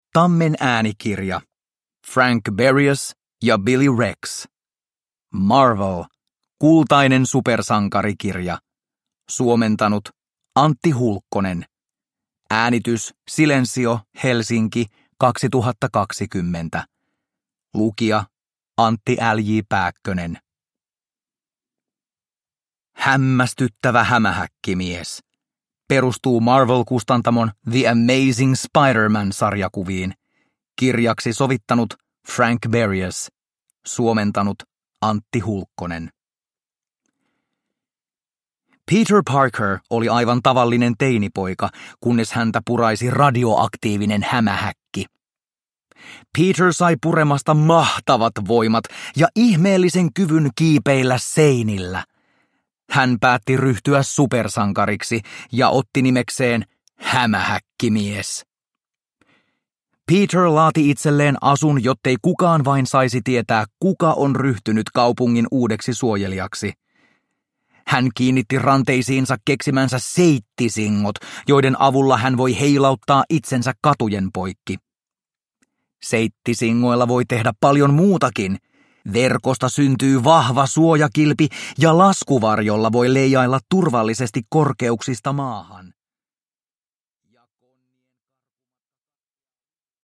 Marvel. Kultainen supersankarikirja – Ljudbok – Laddas ner